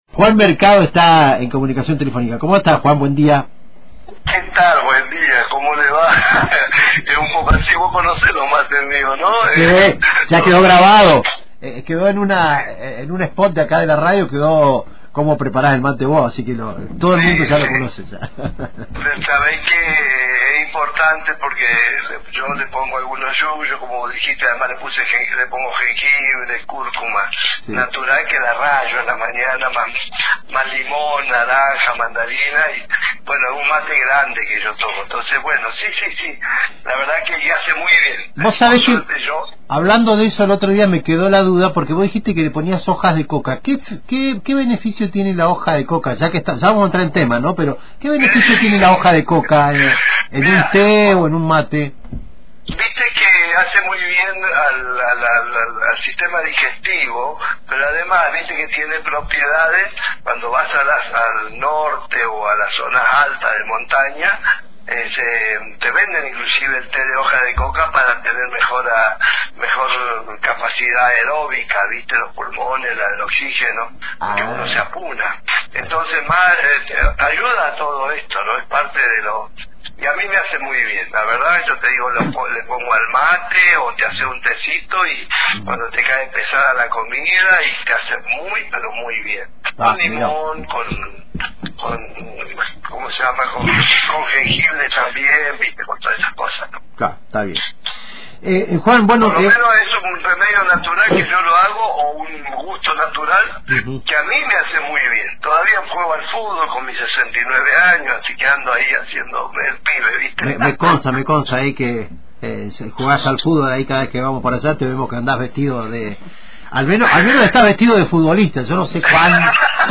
En diálogo con Antena Libre, el concejal de General Roca, Juan Mercado, se refirió al reciente fallecimiento del Papa Francisco, resaltando la trascendencia histórica de su figura y los valores de …